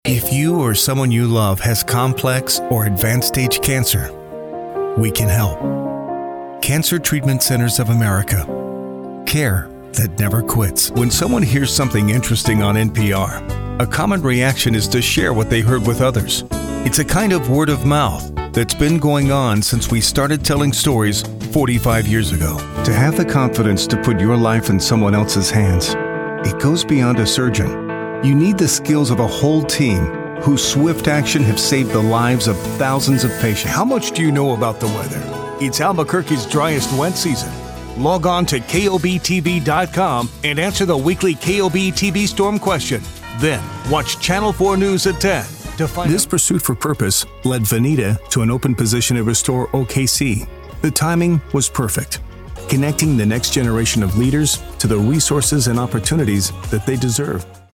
Male
Trans-Atlantic
I have a dynamic voice range, from conversational and relatable, to energetic and powerful announcer.
Microphone: Neumann U87, Sennheiser MKH416